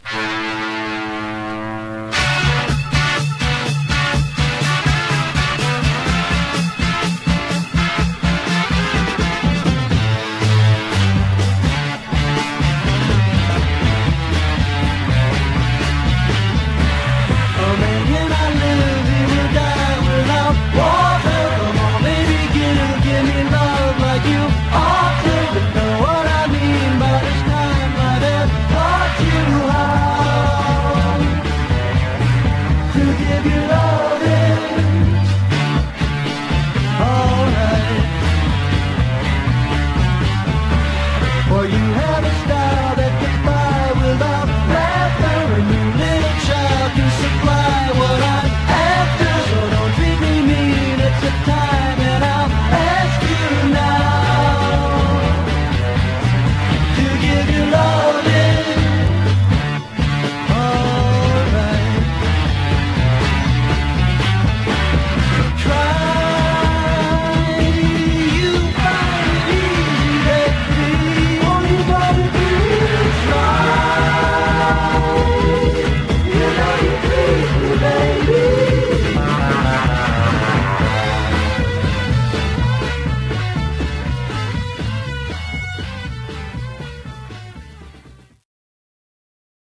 Stereo/mono Mono
Rock